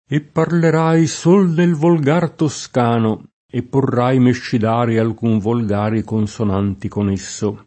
e pparler#i S1l nel volg#r toSk#no e pporr#i meššid#re alk2n volg#ri, konSon#nti kon %SSo]